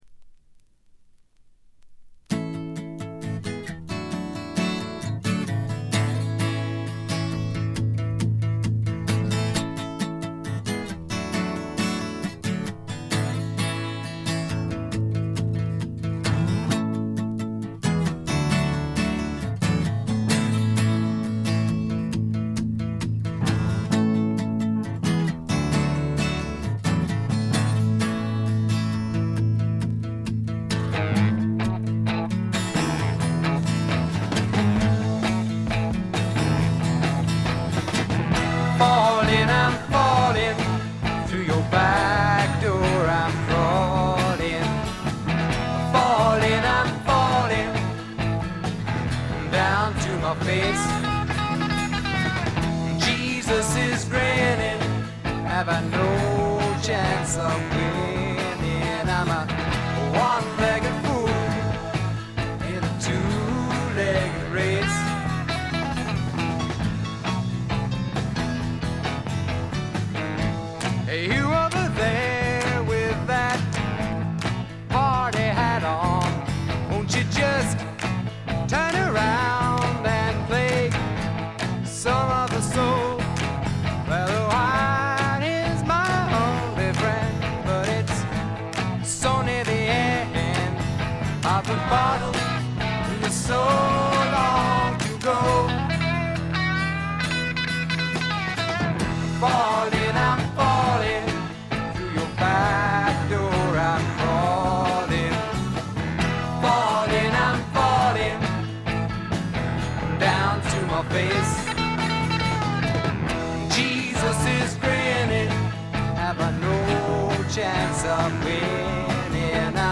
部分試聴ですが、軽微なチリプチ程度。
試聴曲は現品からの取り込み音源です。